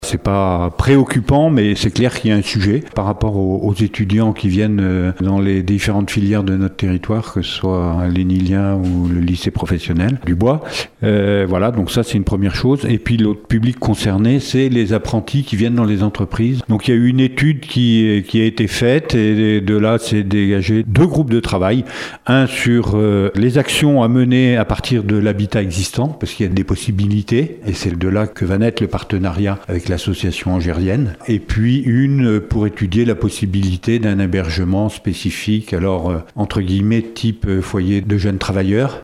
On écoute le président d’Aunis Sud Jean Gorioux :